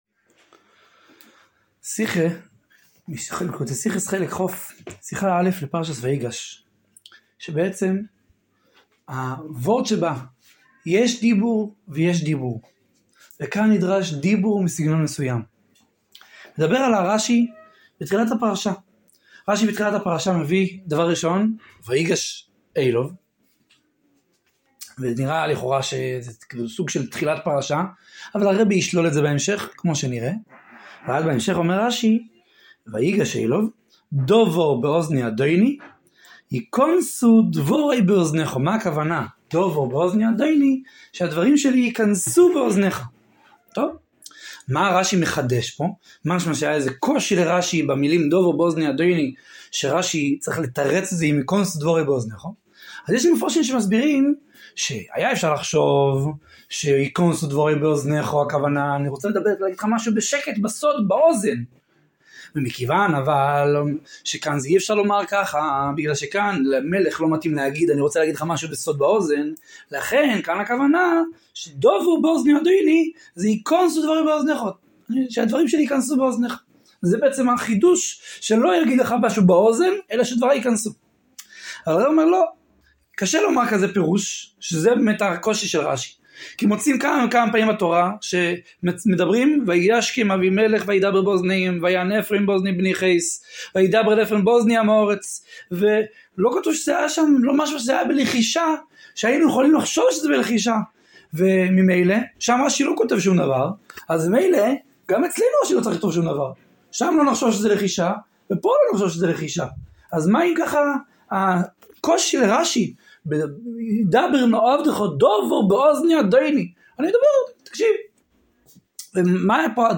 שיעור בעיון עברית